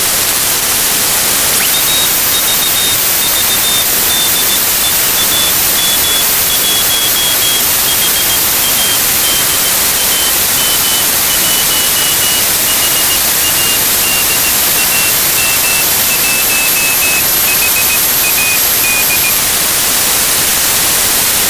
received the CW beacon of SO-121